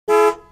جلوه های صوتی
دانلود صدای ماشین 1 از ساعد نیوز با لینک مستقیم و کیفیت بالا